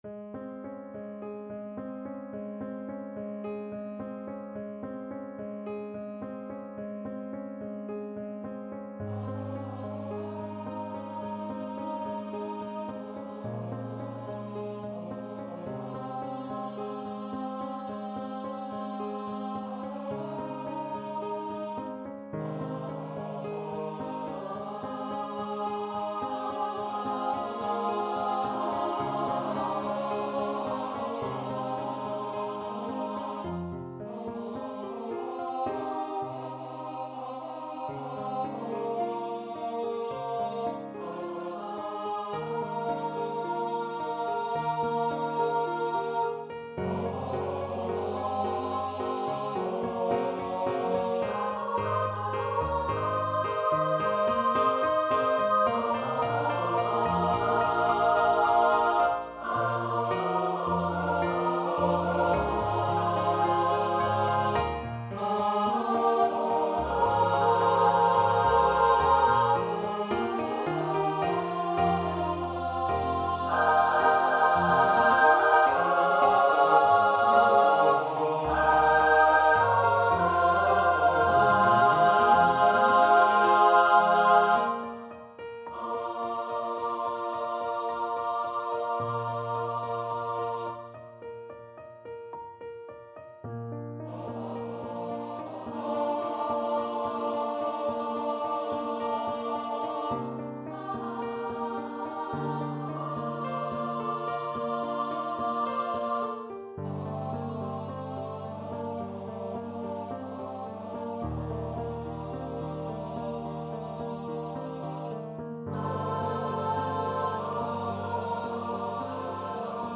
(Demo recording).
SATB, piano
A gentle, buoyant piano ostinato sets the tone